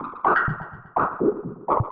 RI_RhythNoise_125-01.wav